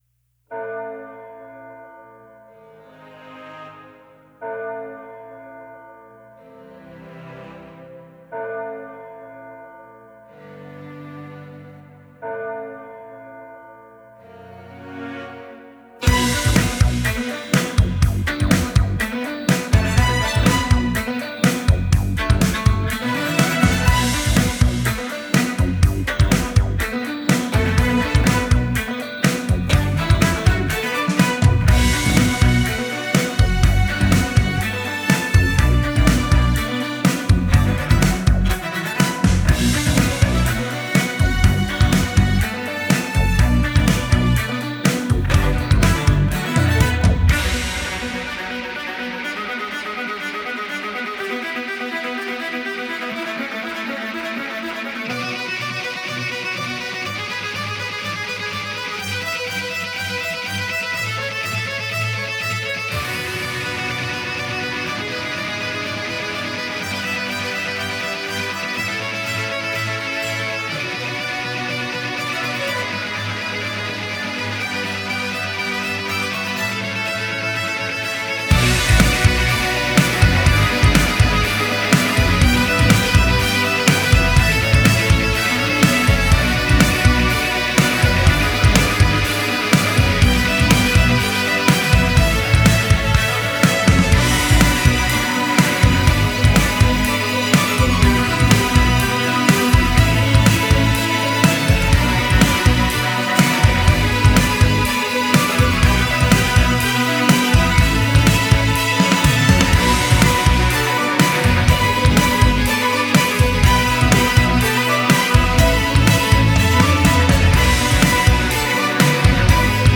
электронный струнный квартет из Лондона, Великобритания
Genre: Electronic, Classical